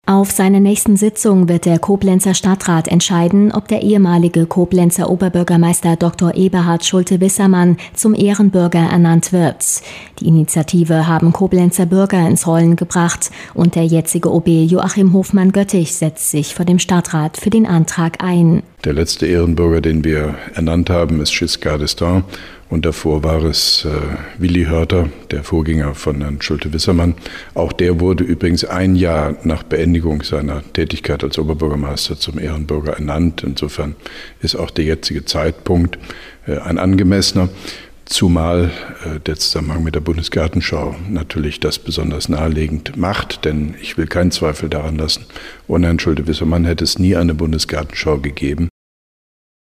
Antenne Koblenz 98,0, Nachrichten 21.07.2011
Mit Stellungnahme von OB Hofmann-Göttig